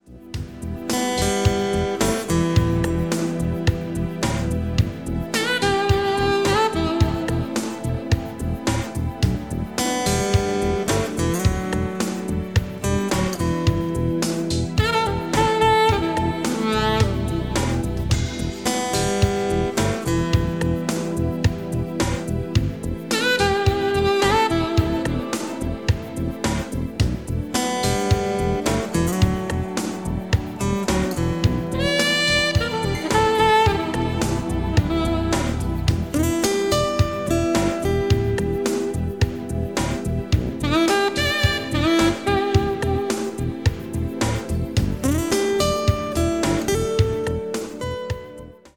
• Качество: 192, Stereo
без слов
инструментальные
Саксофон
Нидерландская саксофонистка и певица